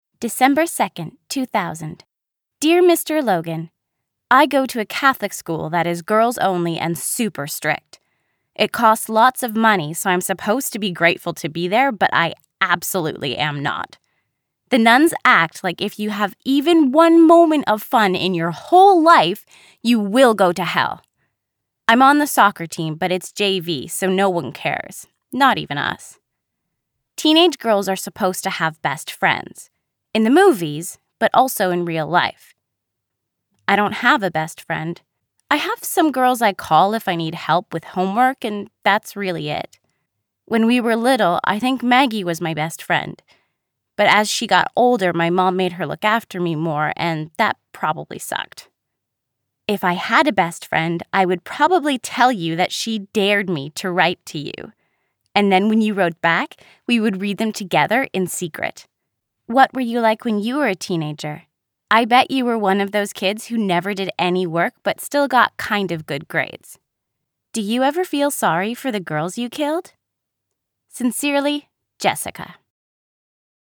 Female
American English (Native)
Native: American-Standard, Canadian High standard: American-California, American-East Coast, American-Midwest, American-New England, American-New York, American-Southern States, Transatlantic
Gaming reel.mp3
Microphone: RØDE NT2‑A